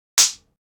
0024_弹额头.ogg